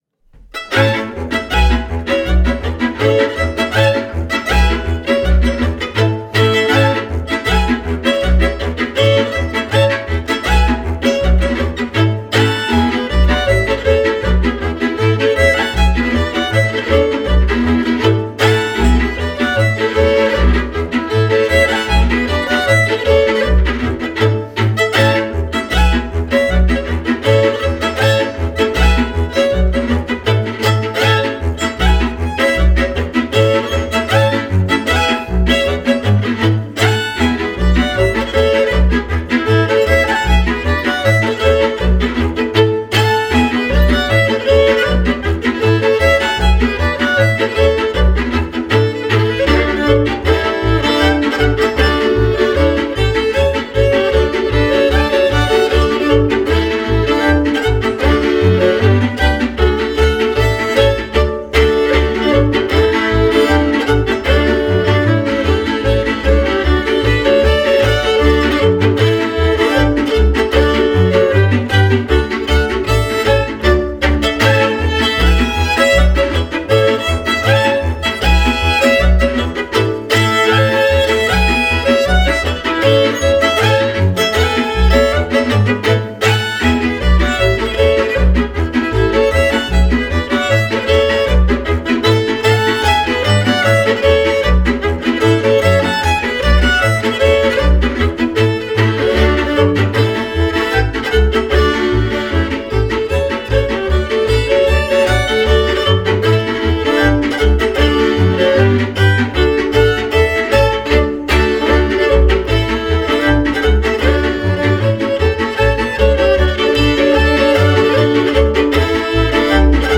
Der Rutscher. Galopp.